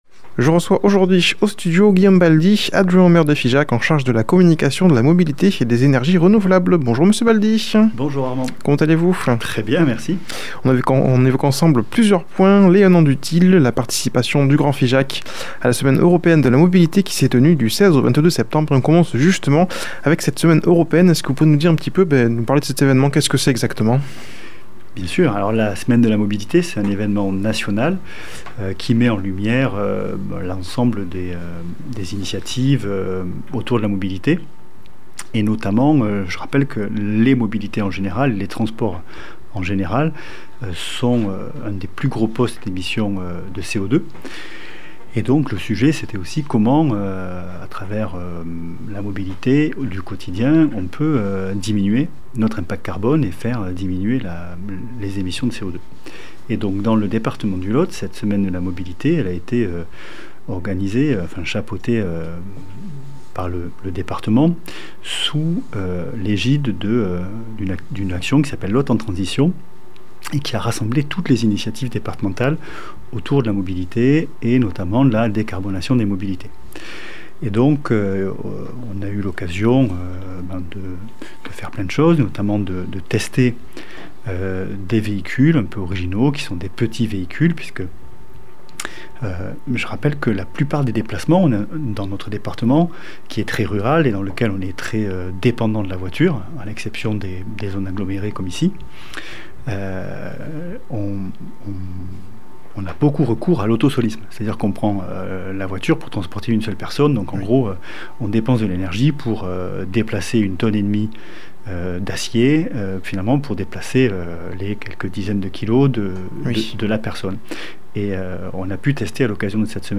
a comme invité au studio Guillaume Baldy adjoint au maire de Figeac en charge de la communication, de la mobilité et des énergies renouvelables. Il vient évoquer les 1 an du TIL et la semaine européenne de la mobilité qui s'est tenue du 16 au 22 Septembre dernier